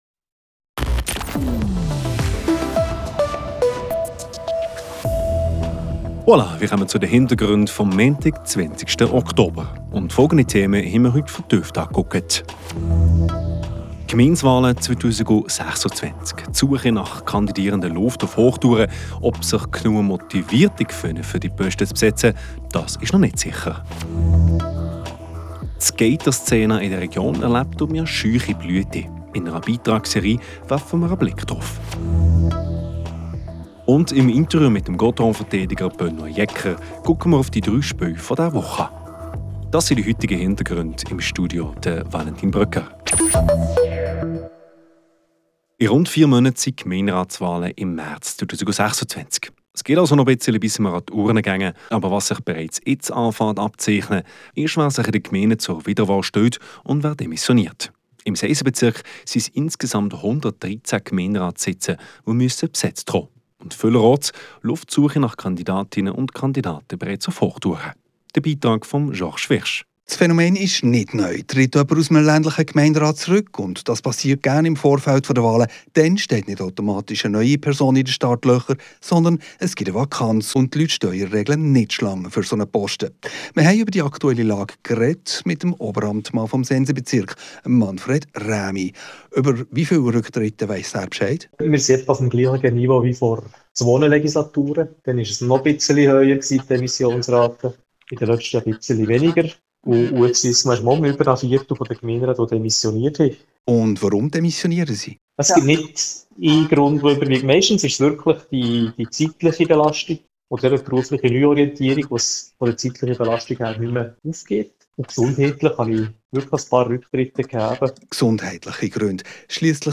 Gemeindewahlen 2026, Skater-Szene, Gottéron-Verteidiger Jecker im Interview ~ Hintergründe des Tages Podcast